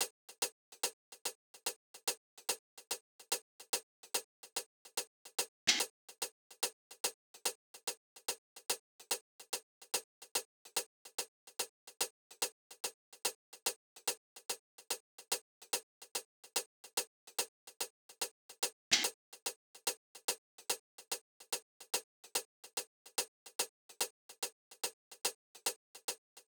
AV_Lights_Tops_145bpm
AV_Lights_Tops_145bpm.wav